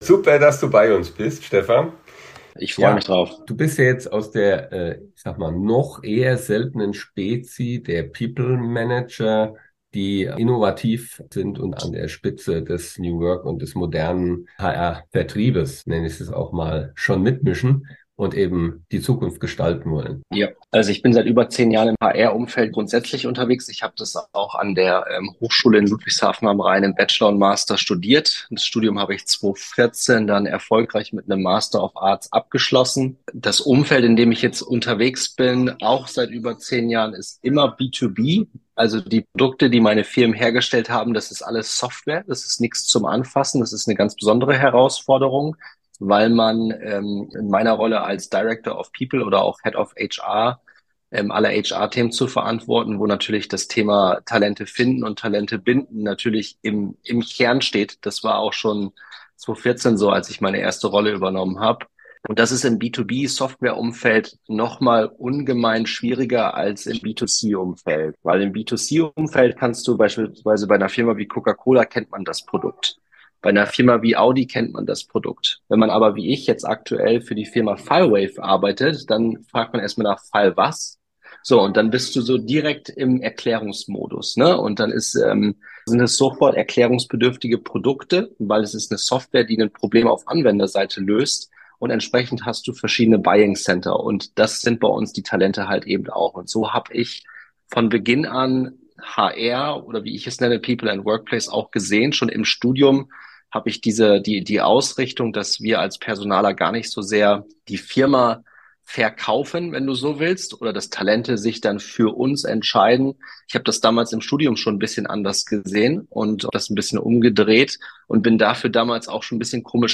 In dieser spannenden Folge unseres Interview-Podcasts widmen wir uns verschiedenen entscheidenden Themen im Kontext des Wandels in Organisationen.